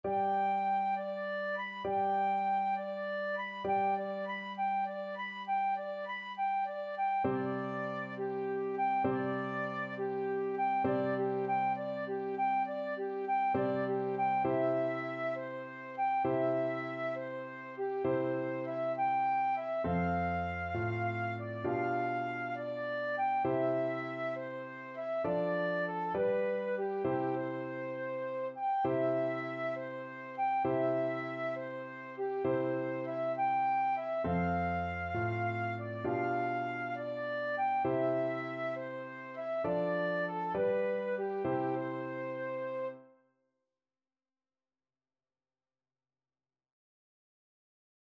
Flute version
Allegretto
6/8 (View more 6/8 Music)
Classical (View more Classical Flute Music)